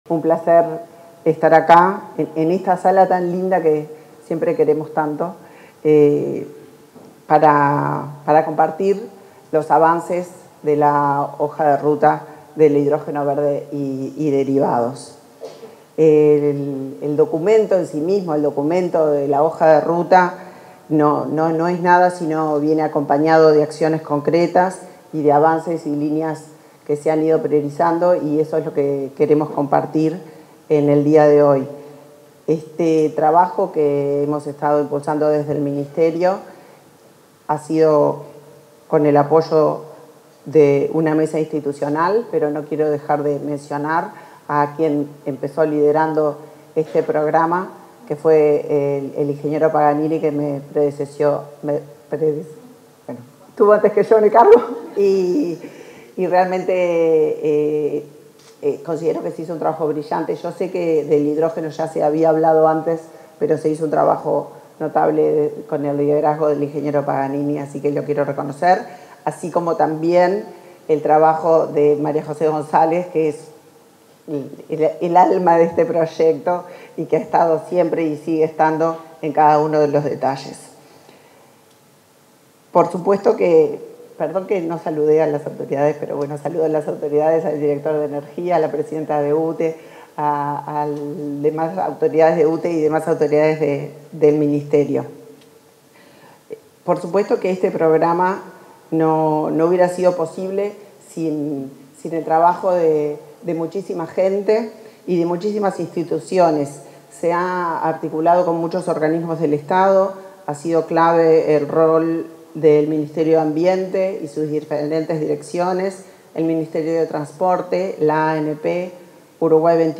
Palabras de la ministra de Industria, Energía y Minería, Elisa Facio